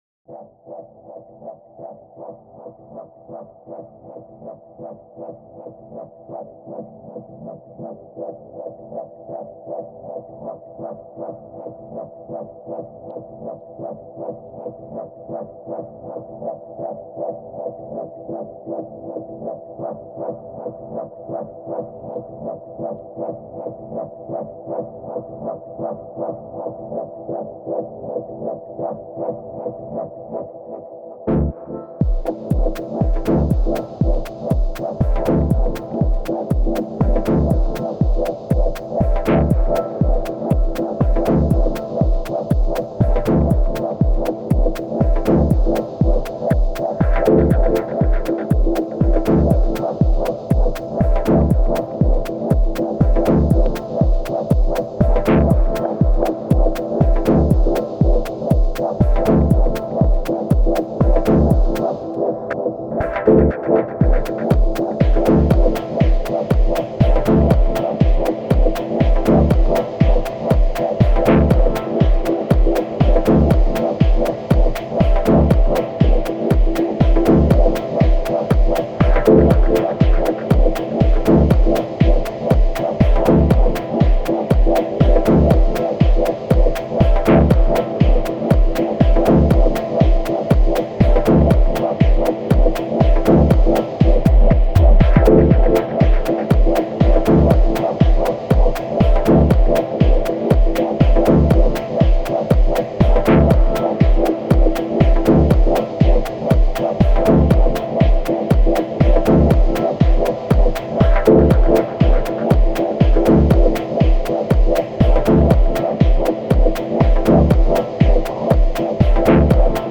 Genre: Ambient/Dub Techno.